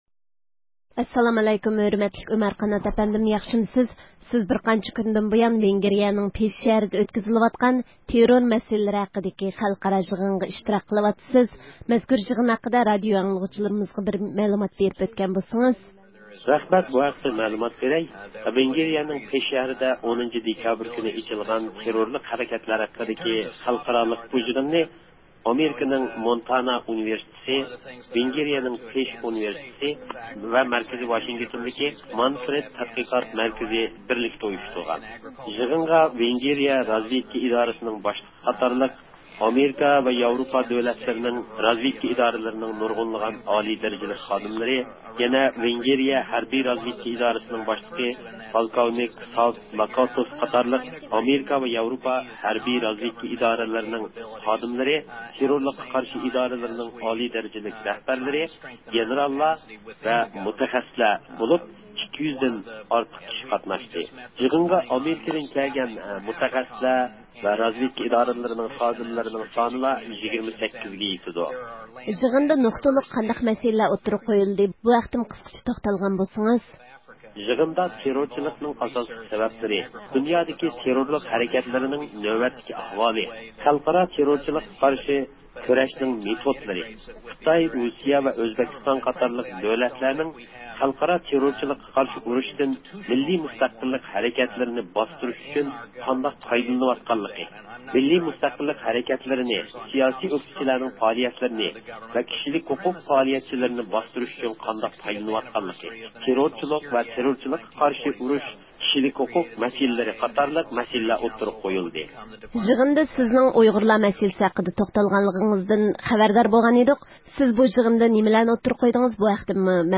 بىز بۇ ھەقتە تەپسىلىي مەلۇماتقا ئېرىشىش ئۈچۈن پىش شەھىرىدىكى يىغىنغا ئىشتىراك قىلىۋاتقان مۇخبىرىمىز